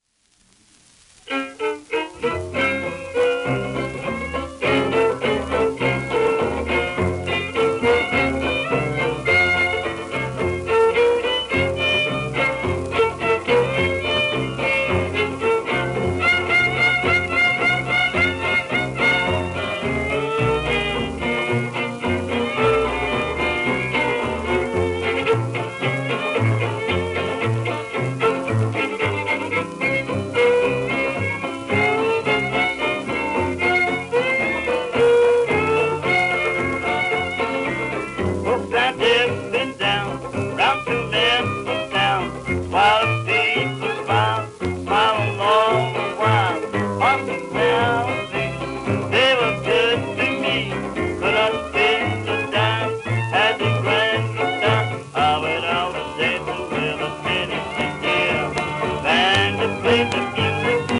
1949年頃録音